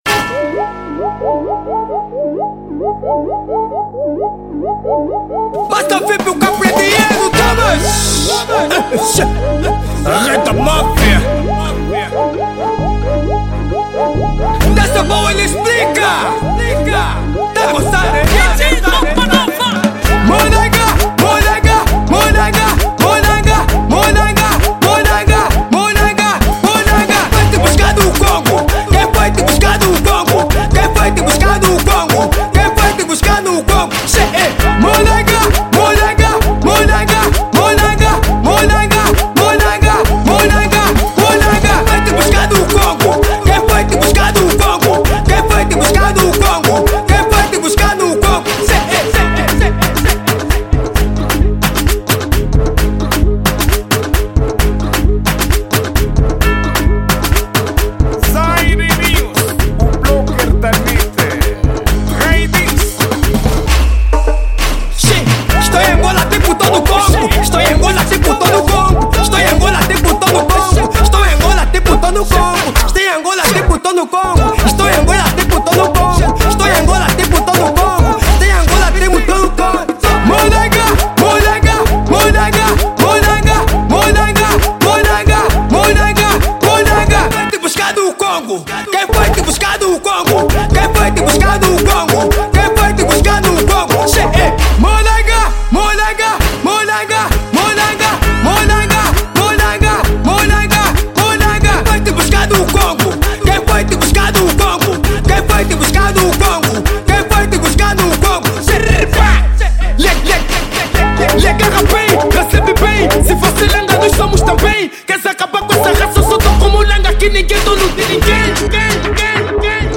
Género: Kuduro